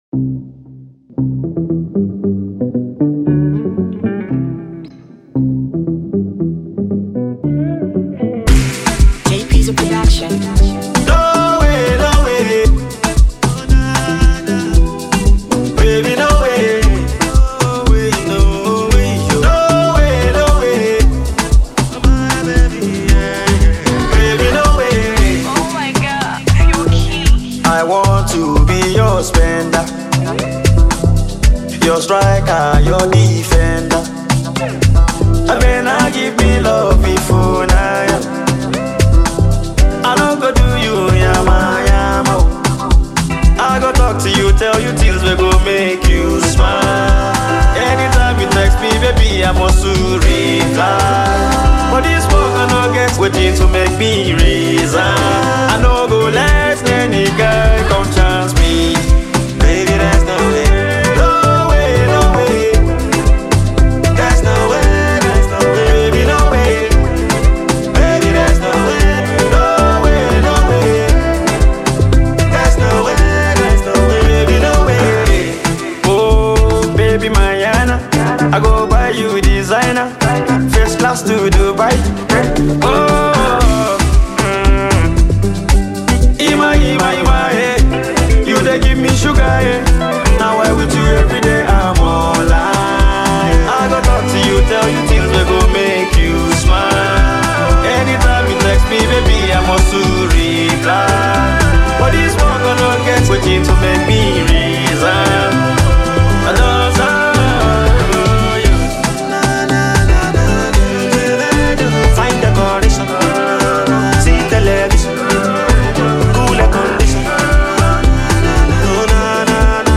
Talented Nigerian singer, songwriter, and guitarist